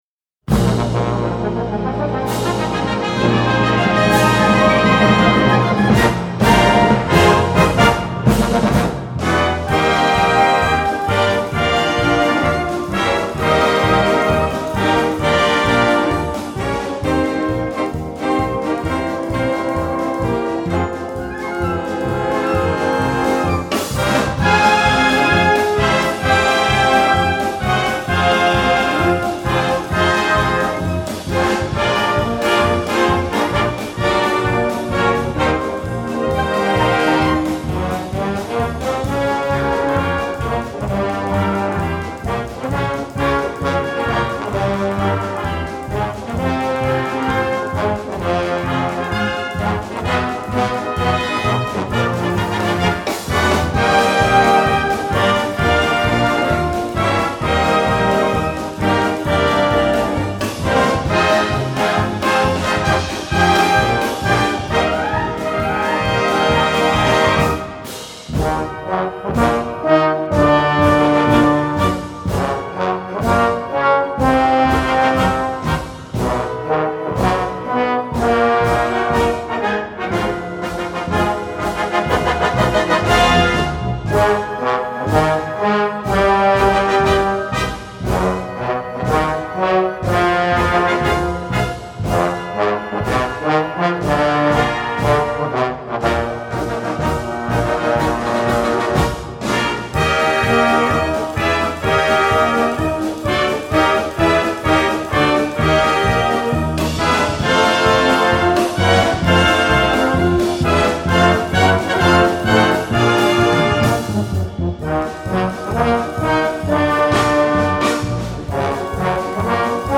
Hörproben der Militärmusiken
"Treffpunkt Europa" gespielt von der Militärmusik Tirol